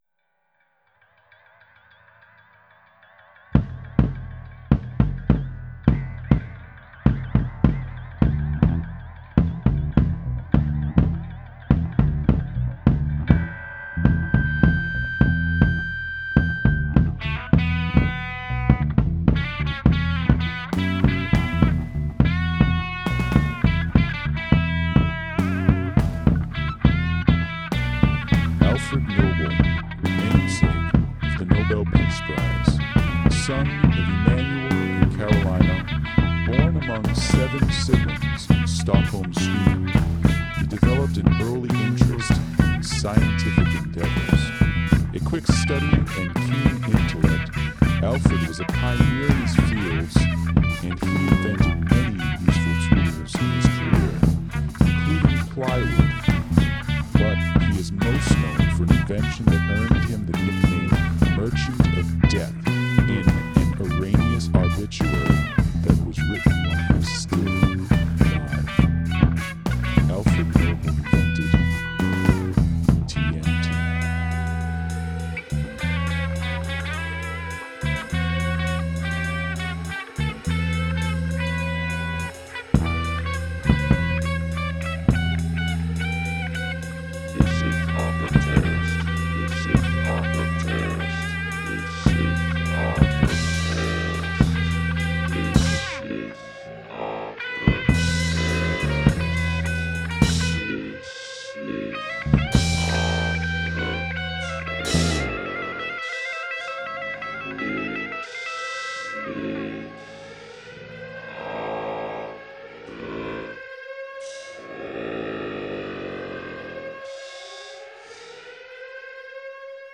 The backing music doesn't help, either.